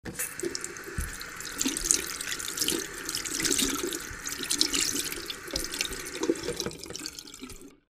wash.wav